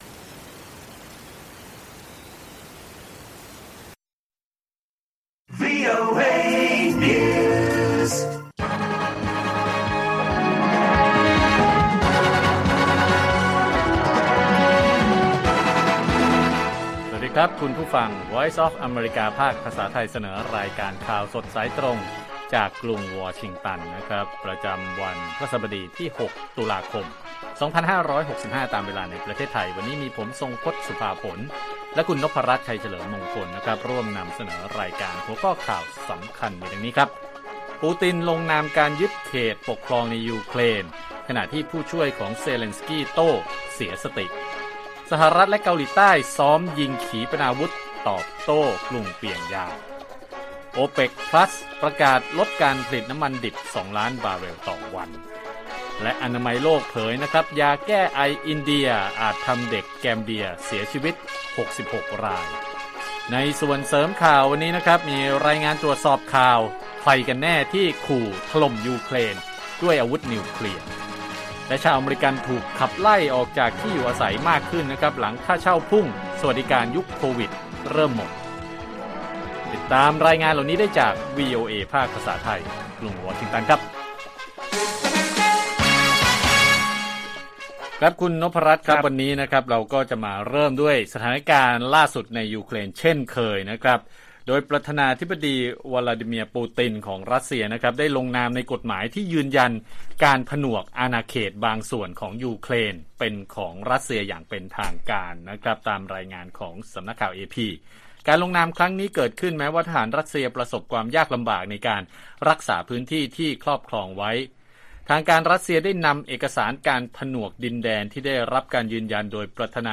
ข่าวสดสายตรงจากวีโอเอไทย พฤหัสบดี 6 ต.ค. 2565